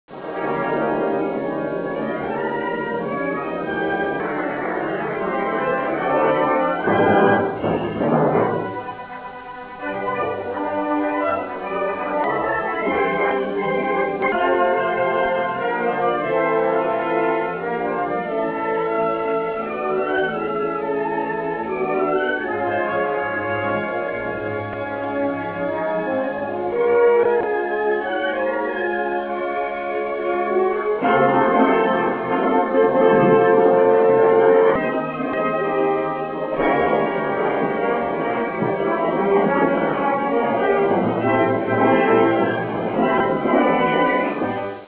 Original track music
Suite